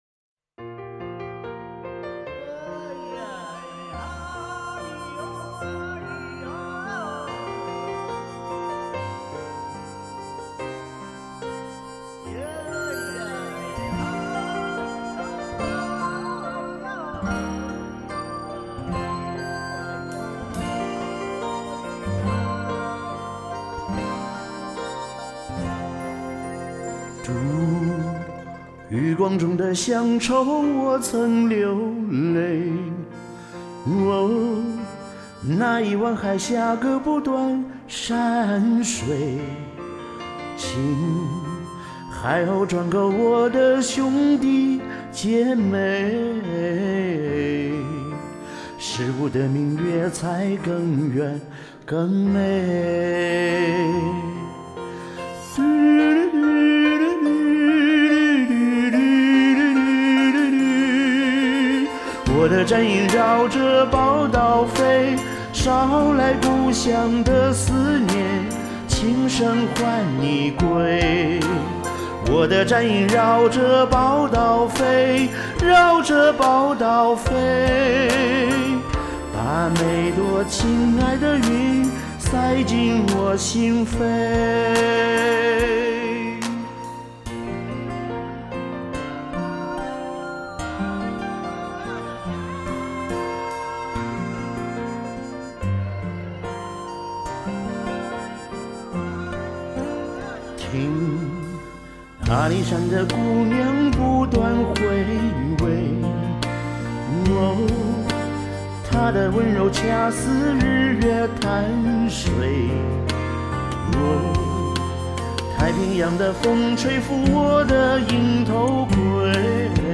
今天发现这首歌的伴奏，迫不及待录了，音有点高，唱得不够飘逸。